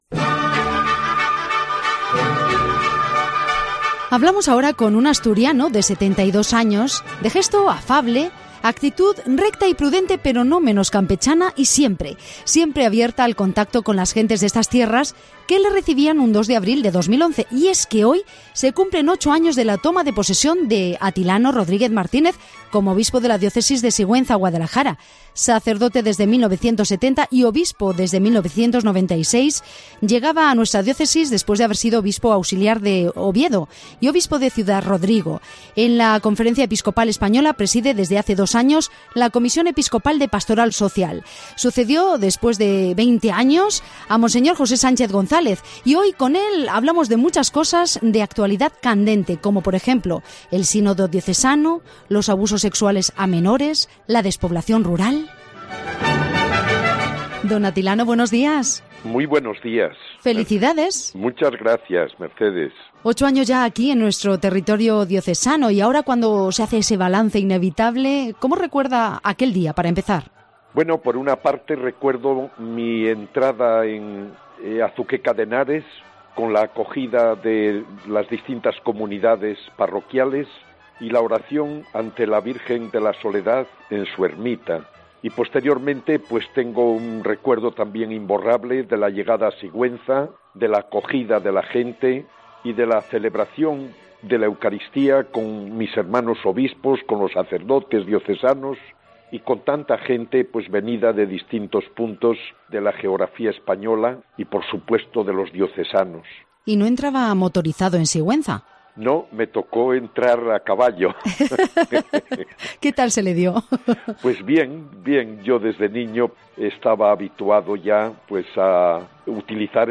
Una realidad de despoblación rural que no deja de constituir una de las grandes preocupaciones para el titular de la Diócesis, con quien hemos hablado en "La Mañana" de Cope Guadalajara. Pero, además, Don Atilano ha abordado otros asuntos de candente actualidad como el Sínodo Diocesano o los abusos sexuales a menores